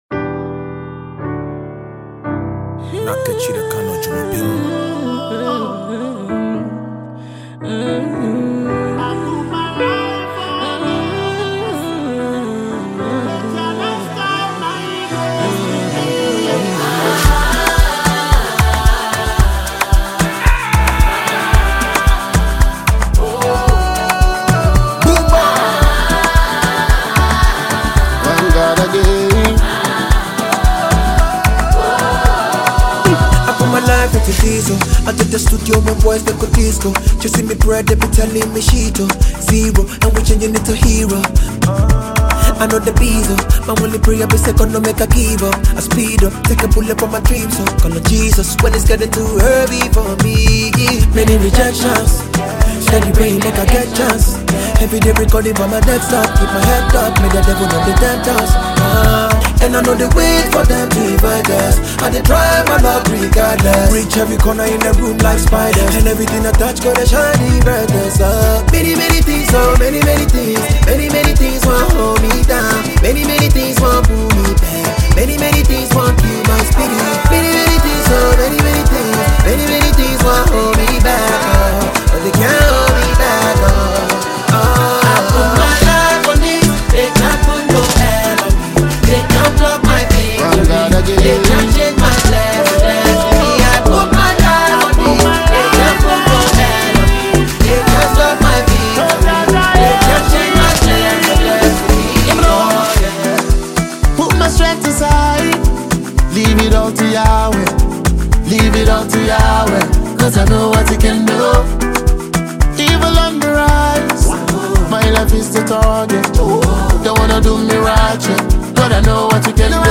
Ghana Music
Ghanaian afrobeat dancehall singer.